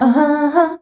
AHAAA   C.wav